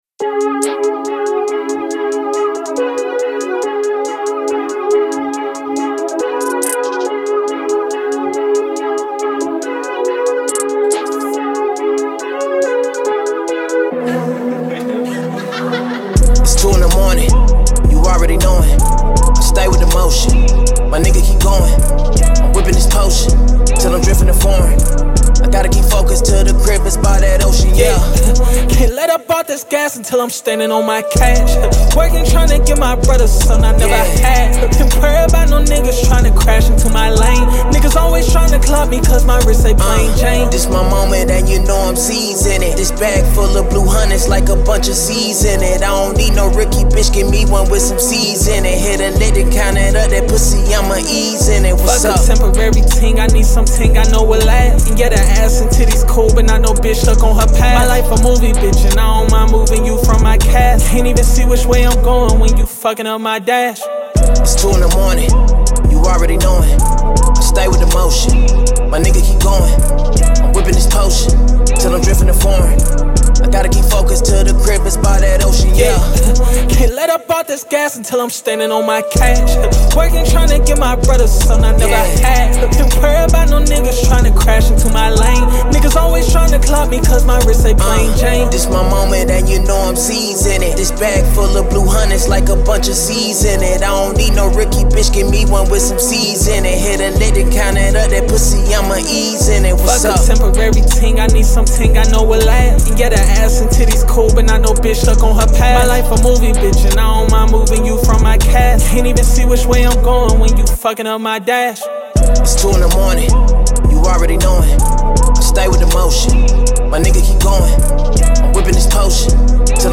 Hip Hop
Em
Street themed, hustler music
Braggadious